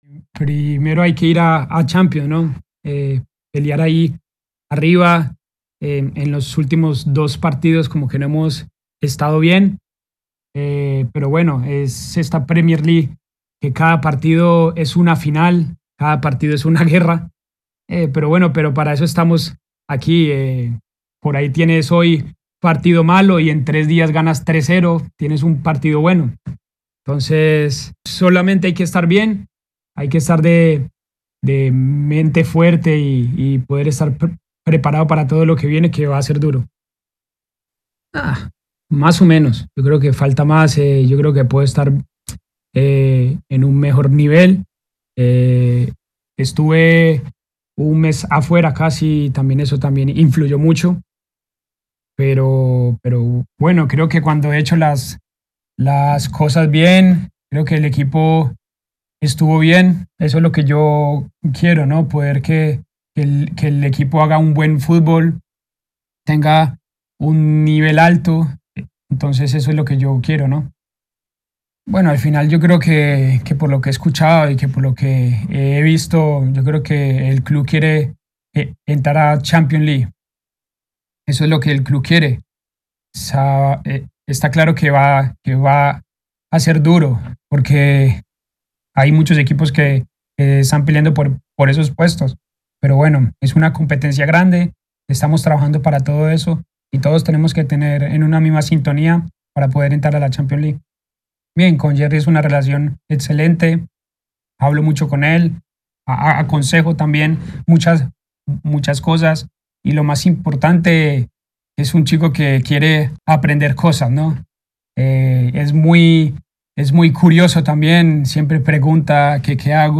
(James Rodríguez en entrevista con Telemundo Deportes)